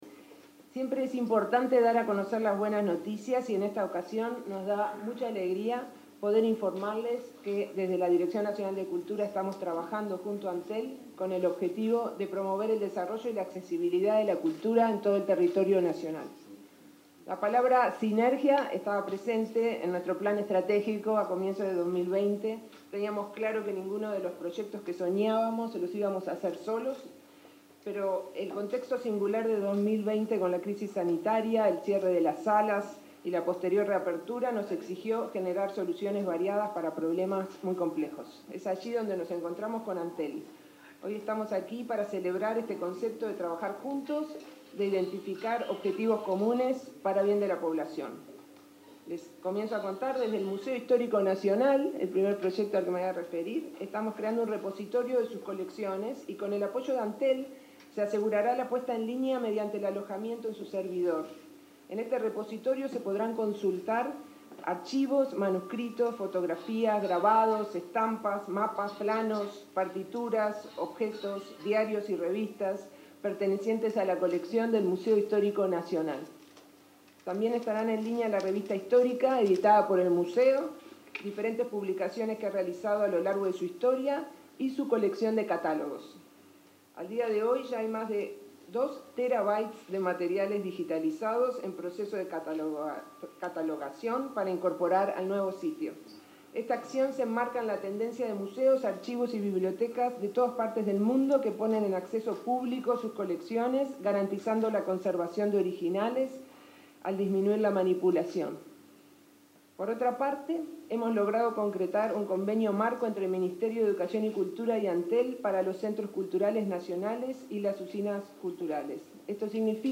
Conferencia de prensa de Da Silveira, Gurméndez y Wainstein
Conferencia de prensa de Da Silveira, Gurméndez y Wainstein 12/08/2021 Compartir Facebook X Copiar enlace WhatsApp LinkedIn El ministro de Educación y Cultura, Pablo da Silveira, participó, este jueves 12 de agosto, de una conferencia de prensa en la que se anunciaron apoyos al sector cultural, acompañado por el presidente de Antel, Gabriel Gurméndez, y la directora nacional de Cultura, Mariana Wainstein.